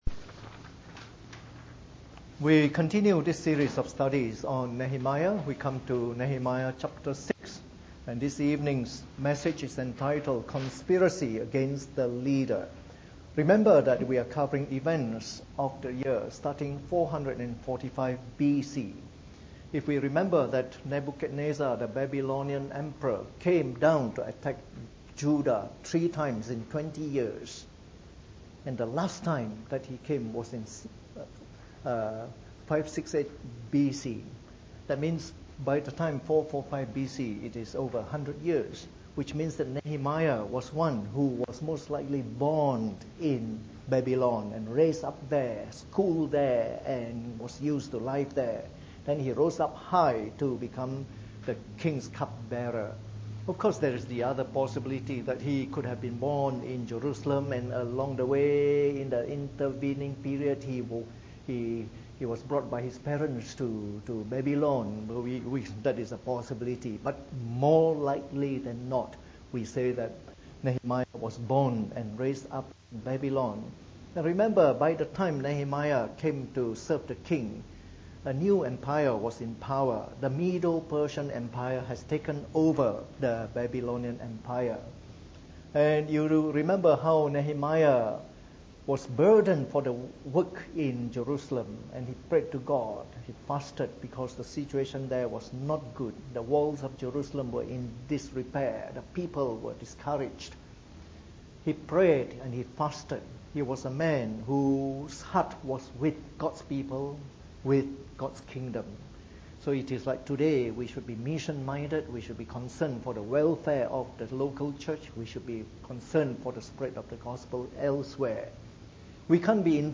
Preached on the 4th of June 2014 during the Bible Study, from our series of talks on the Book of Nehemiah.